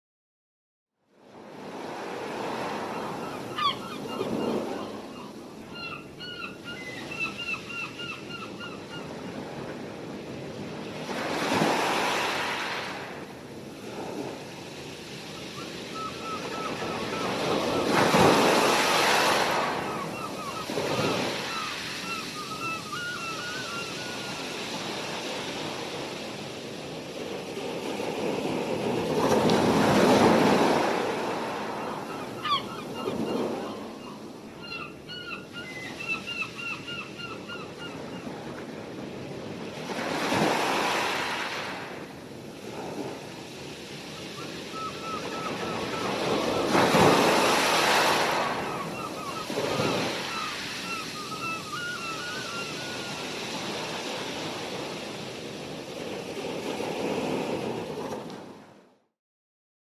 Звуки чайки
Звук океанских волн и крик чаек